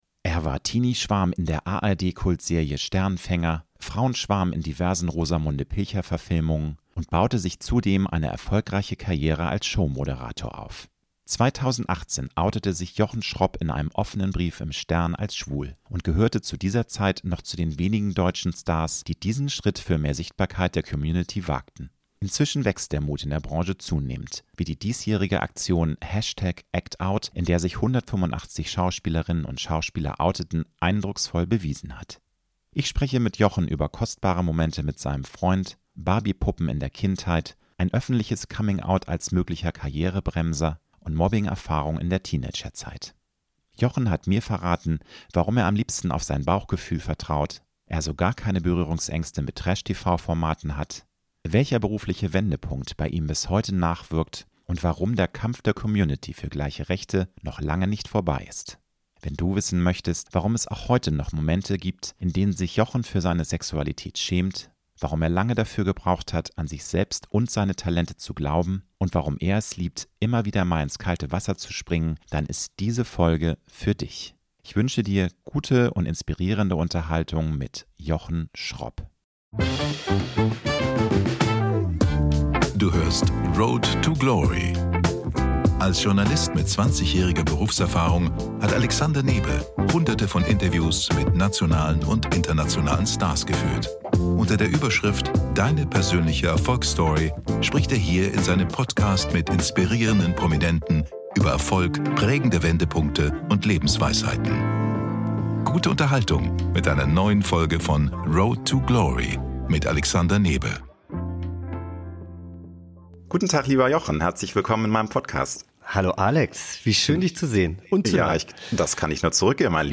Ich spreche mit Jochen über kostbare Momente mit seinem Freund, Barbie-Puppen in der Kindheit, ein öffentliches Coming-Out als möglicher Karriere-Bremser und Mobbing in der Teenagerzeit. Jochen hat mir verraten, warum er am liebsten auf sein Bauchgefühl vertraut, er so gar keine Berührungsängste mit Trash-TV-Formaten hat, welcher berufliche Wendepunkt bei ihm bis heute nachwirkt und warum der Kampf der Community für gleiche Rechte noch lange nicht vorbei ist.